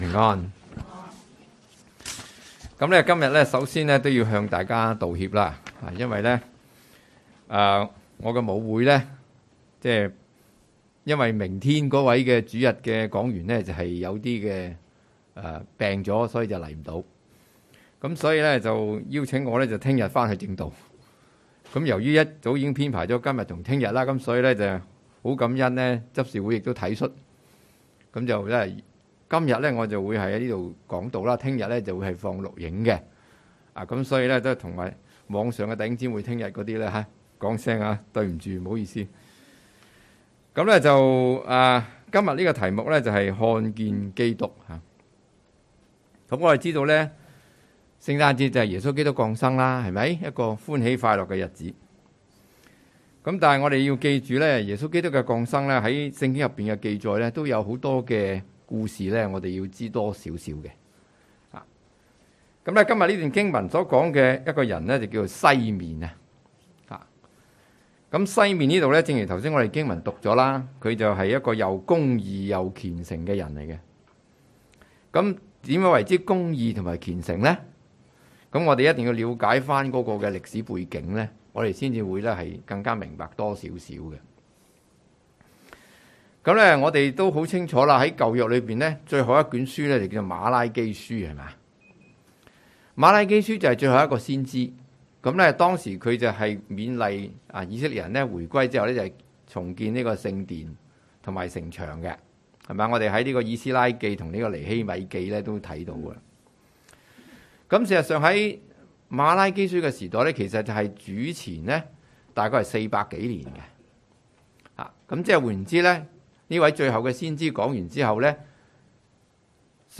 講道 ：看見基督